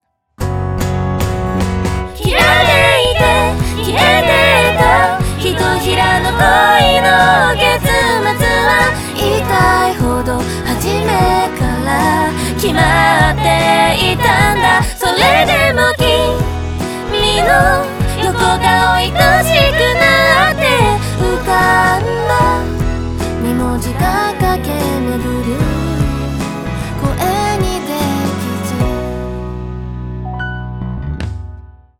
ダブルボーカルやコーラスのタイミング合わせは、VocAlign 6を使えば簡単に行えます。
VocAlignBefore.wav